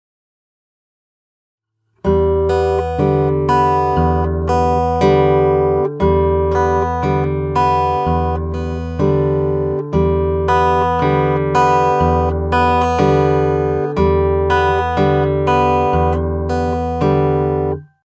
All of the rhythms in these examples have been kept fairly simple, and in 4/4 time.
You’ll notice from the examples that the thumb is basically playing quarter notes alternating between the bottom E string and the D string.
Alternating Bass Fingerstyle 8
audio for this alternating bass fingerstyle pattern.